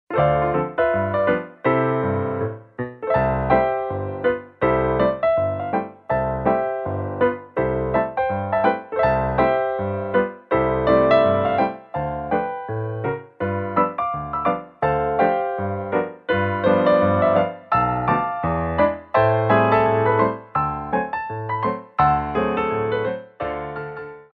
QUICK TEMPO